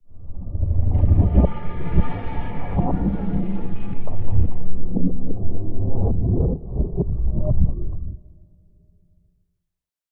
Minecraft Version Minecraft Version snapshot Latest Release | Latest Snapshot snapshot / assets / minecraft / sounds / ambient / cave / cave20.ogg Compare With Compare With Latest Release | Latest Snapshot
cave20.ogg